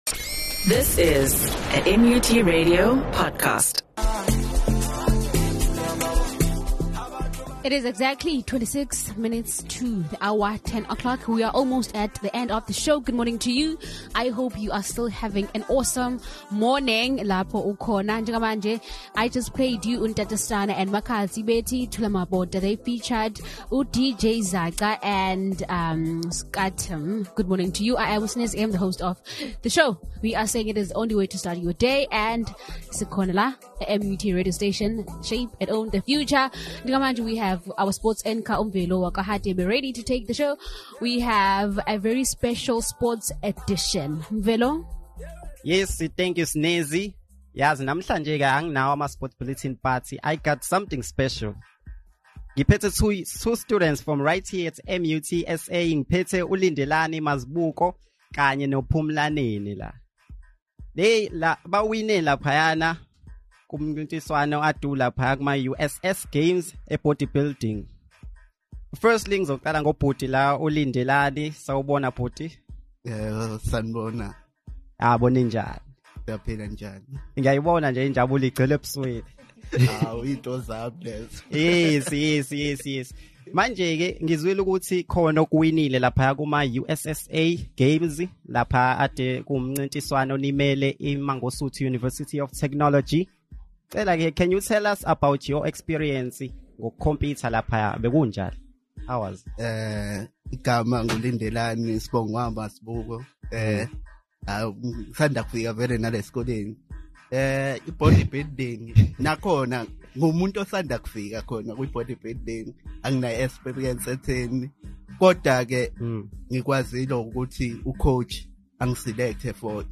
The Vuk'bangene Breakfast show had an interview